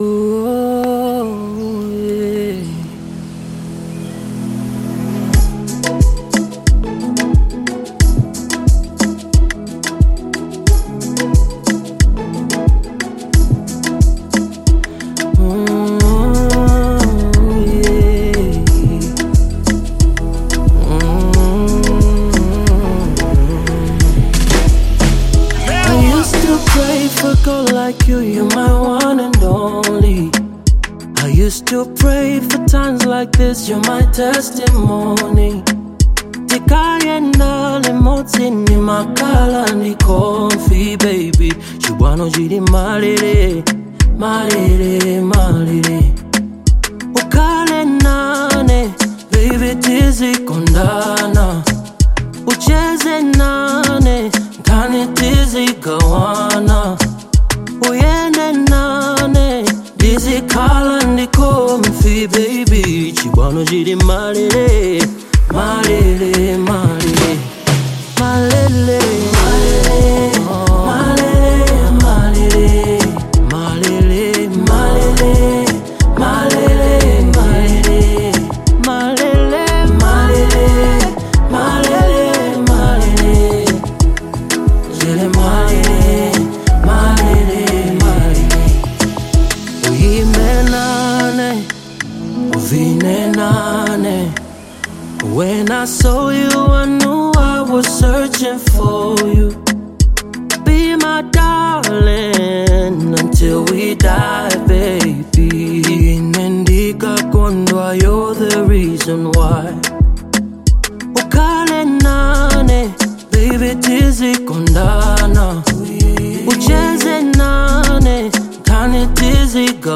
Genre : RnB
characterized by smooth harmonies and rhythmic percussion
soulful vocals